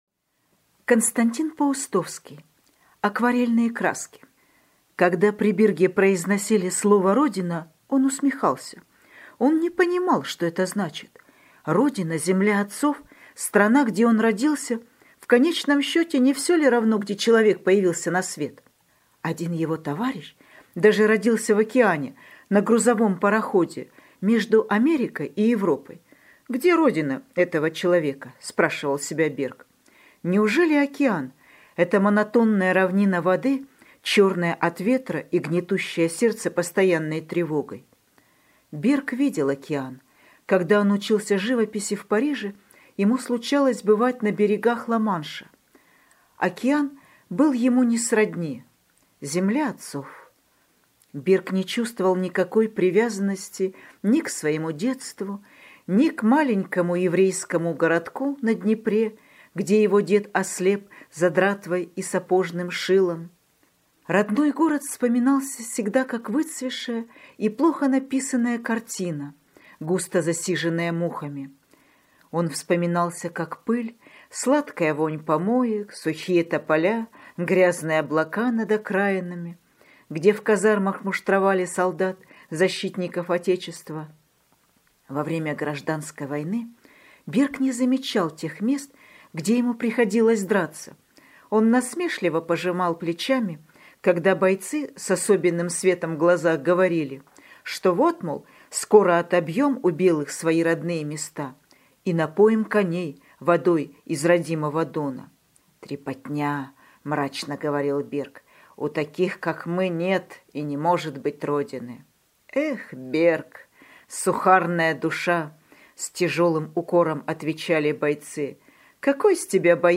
Акварельные краски - аудио рассказ Паустовского - слушать онлайн